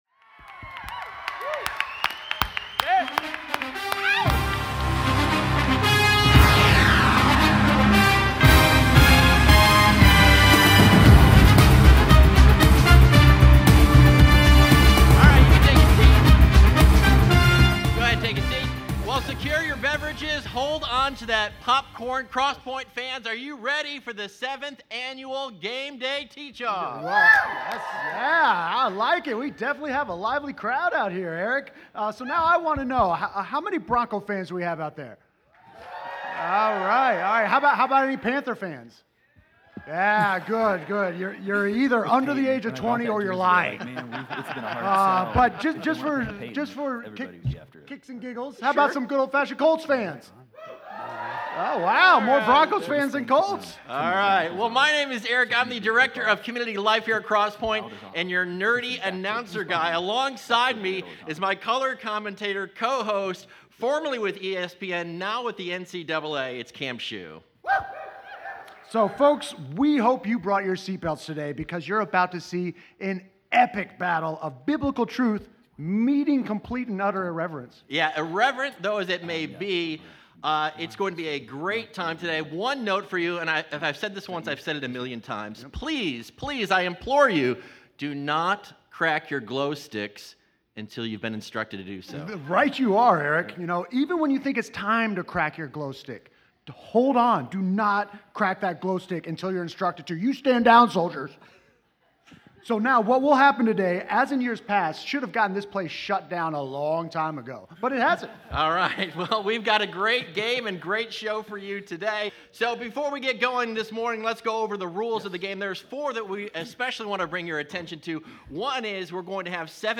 So, when you merge one of America’s favorite game days with church, you end up with an irreverent teaching competition that turns “church” into a spectator sport.
There will be a stadium environment, music you’ll know, a head-to-head teaching competition including color commentary, a rocking half-time show, as well as low lights and easy exits if you change your mind!